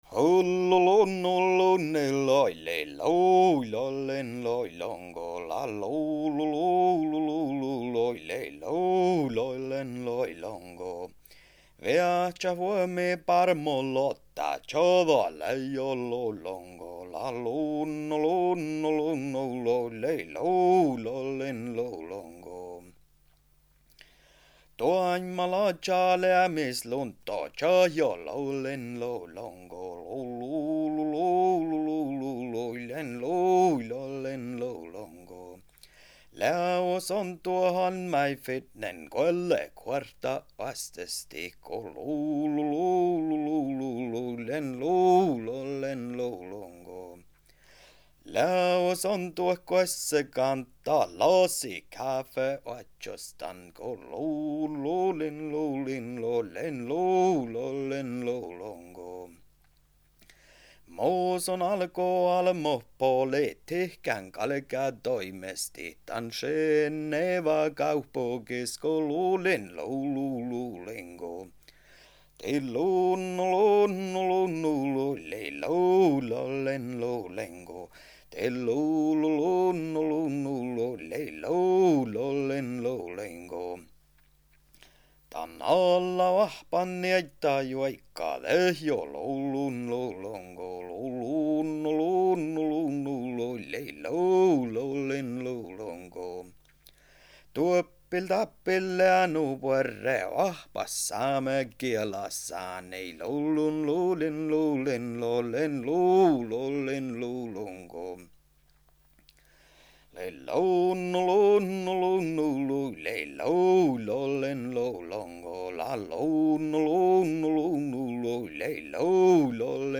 Finally Some yoiks